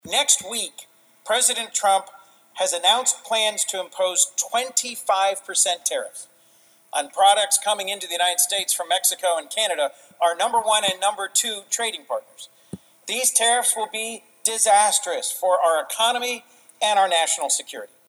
Senator Coons Speaks on Senate Floor to Request Unanimous Consent for Tariff Bill
Senator Chris Coons this afternoon spoke on the Senate floor to request unanimous consent on his bill, the Stopping Tariffs on Allies and Bolstering Legislative Exercise of (STABLE) Trade Policy Act, ahead of the expected implementation of President Trump’s tariffs on Canada and Mexico next week…